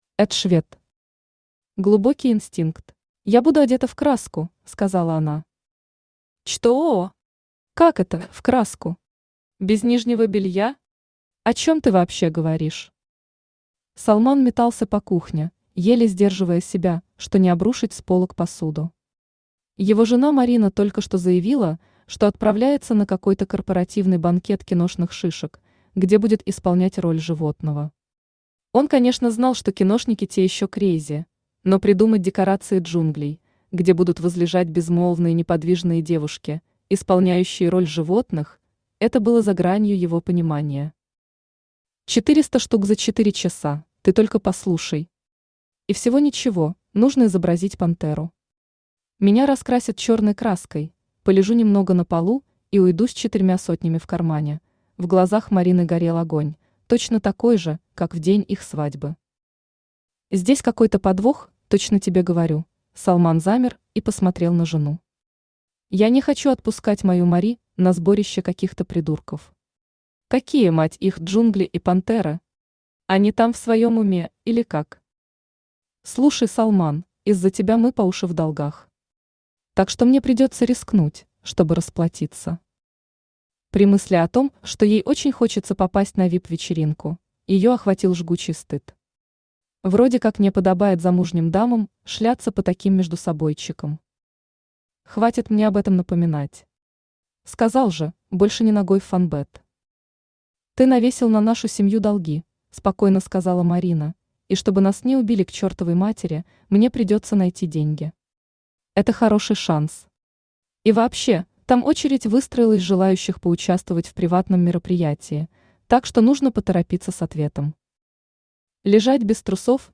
Аудиокнига Глубокий инстинкт | Библиотека аудиокниг
Aудиокнига Глубокий инстинкт Автор Эд Швед Читает аудиокнигу Авточтец ЛитРес.